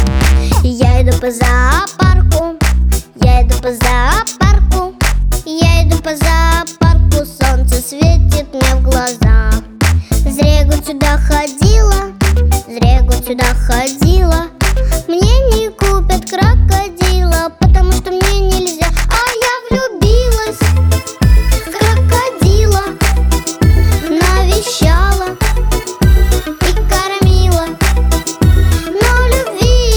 Жанр: Русская поп-музыка / Русский рок / Русские
# Children's Music